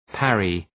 Shkrimi fonetik {‘pærı}